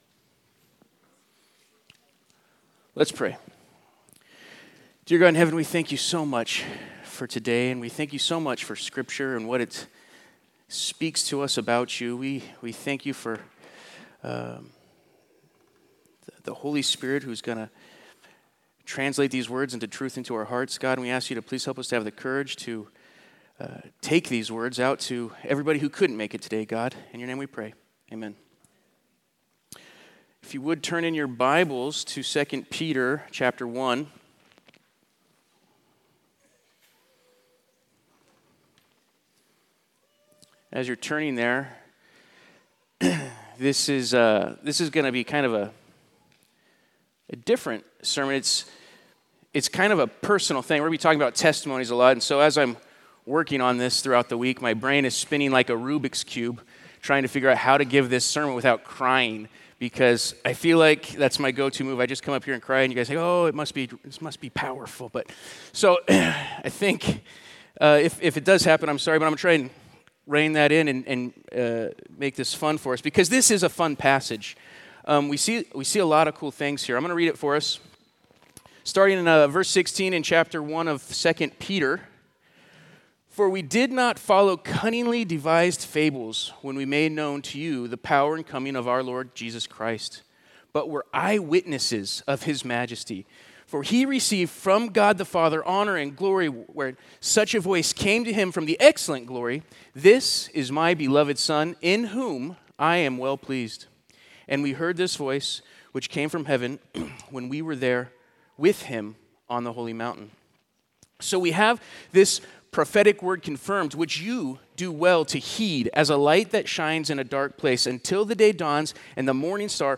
All Sermons | Cottonwood Community Church